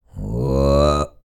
TUVANGROAN16.wav